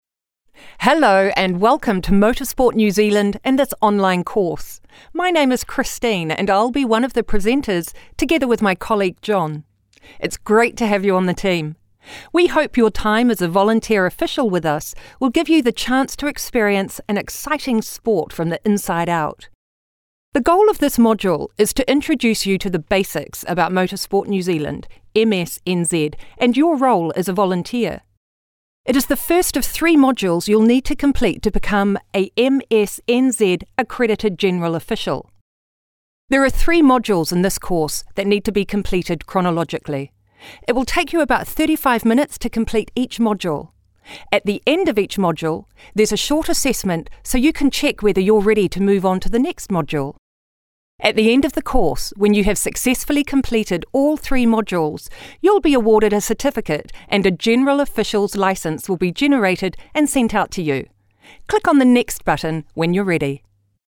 Adult, Young Adult
English | New Zealand
international english
e-learning
friendly
warm
well spoken